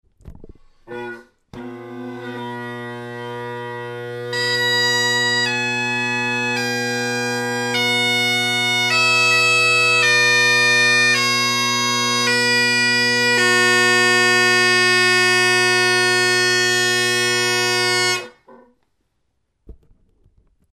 A couple of clips of the scale played on the highland bagpipes with the low G substituted for another note.
Low E – obtained by taping over both tone holes entirely and extending the length of the chanter just a little bit with a ring of tape on the very bottom, less than a centimeter in length. All the grace notes on the bottom hand work well enough, though throws and such don’t work very well. All the top hand grace notes produce a squeal.
Chris Apps poly chanter with a ridge cut reed. 1950’s Henderson pipes, Selbie drone reeds.